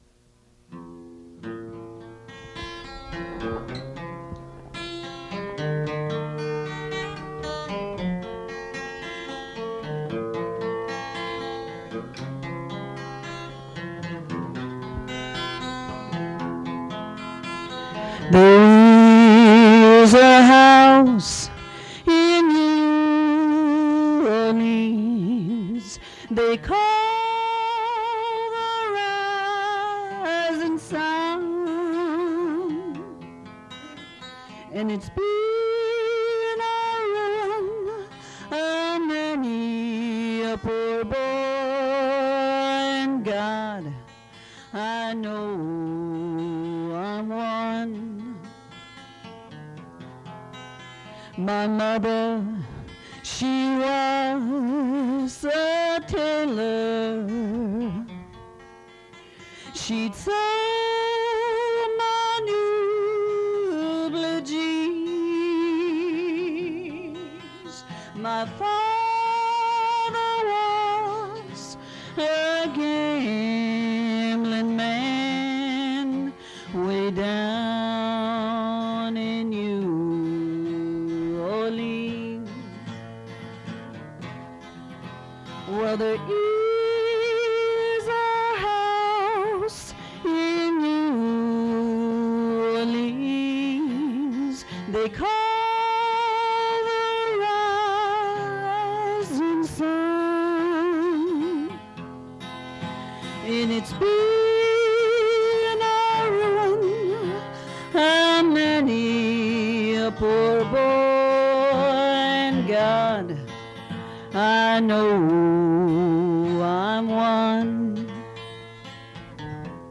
Fait partie de Acoustic reinterpretation of rock music